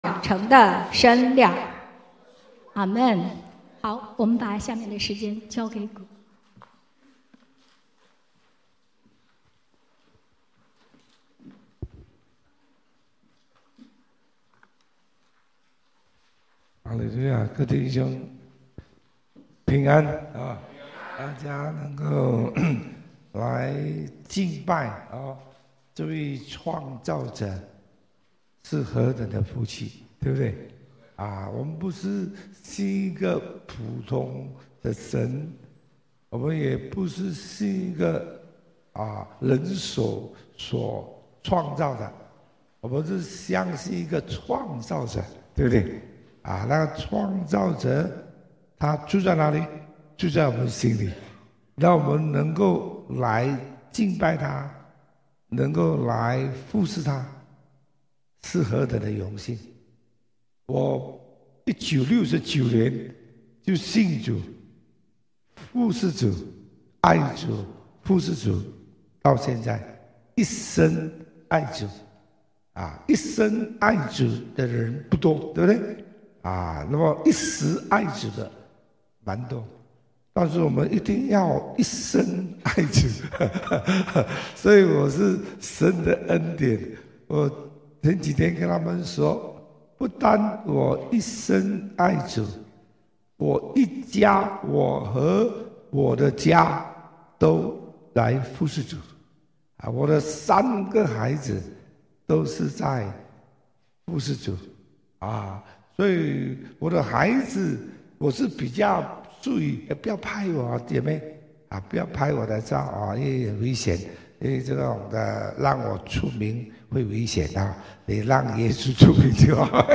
團隊配搭（主日崇拜中文第一堂） – 紐西蘭恩慈台福基督教會